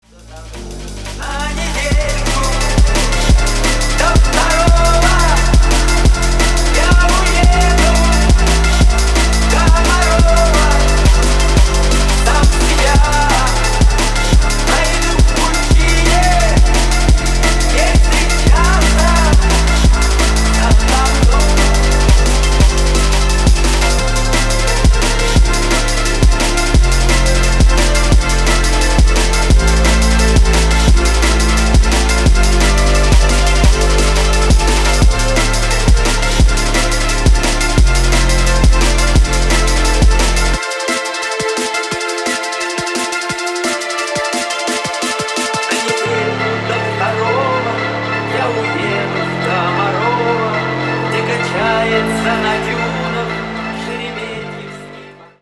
• Качество: 320, Stereo
drum n bass
drum&bass